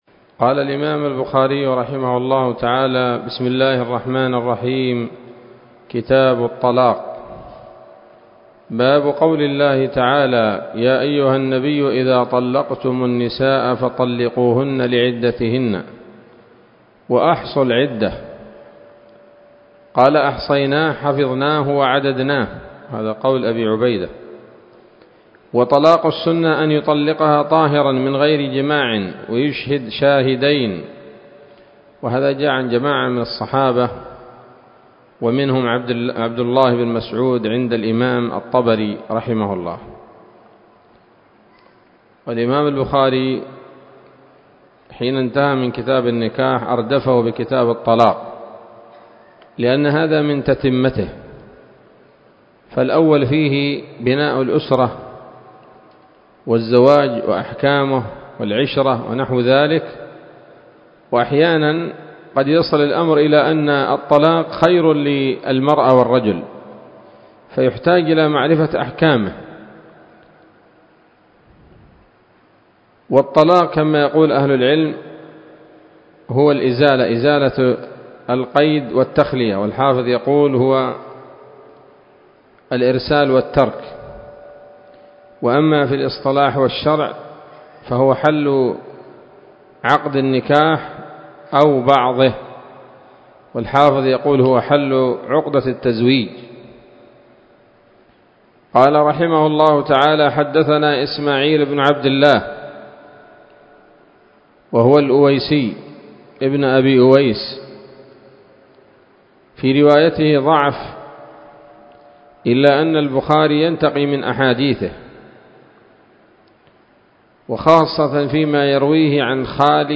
الدرس الأول من كتاب الطلاق من صحيح الإمام البخاري